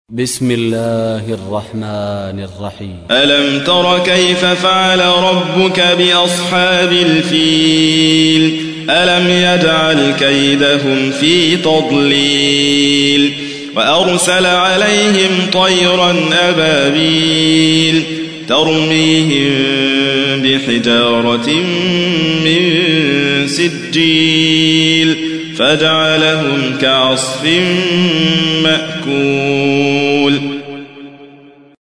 تحميل : 105. سورة الفيل / القارئ حاتم فريد الواعر / القرآن الكريم / موقع يا حسين